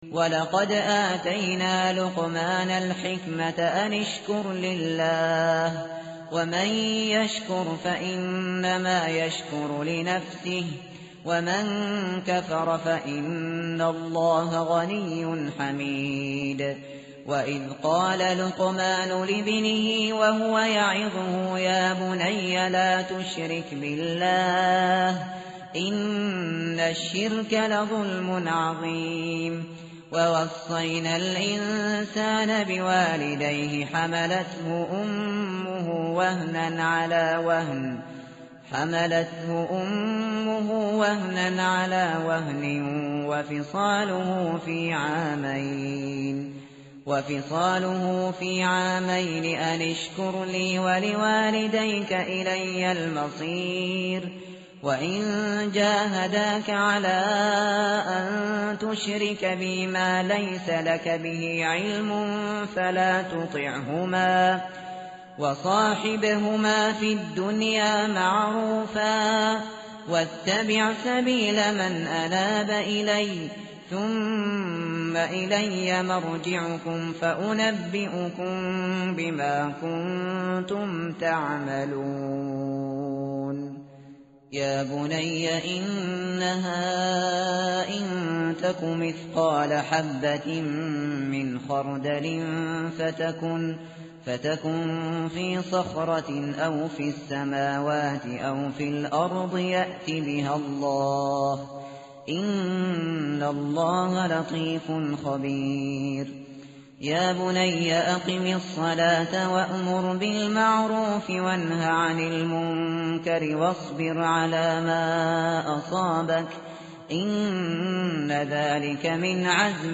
tartil_shateri_page_412.mp3